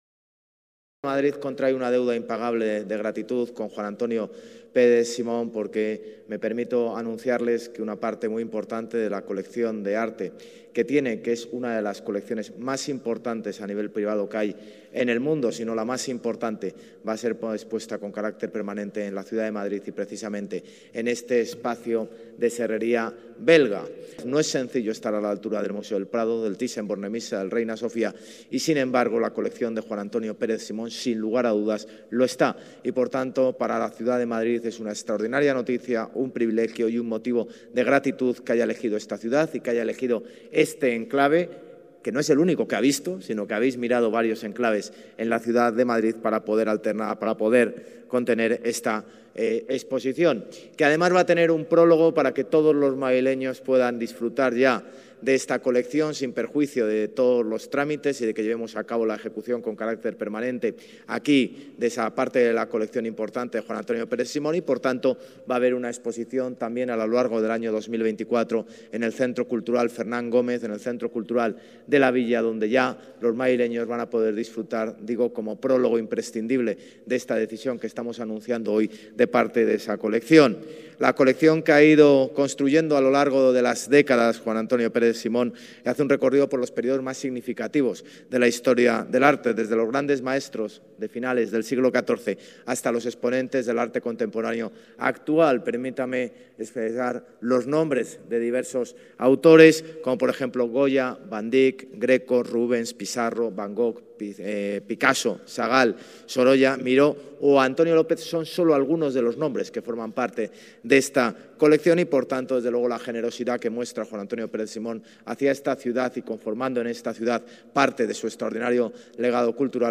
Allí, el alcalde de Madrid, José Luis Martínez-Almeida, y el empresario y coleccionista Juan Antonio Pérez Simón han manifestado hoy su interés mutuo en este proyecto, que se concretará a lo largo del presente mandato a través del Área de Cultura, Turismo y Deporte, dirigida por Marta Rivera de la Cruz.
José Luis Martínez-Almeida, alcalde de Madrid: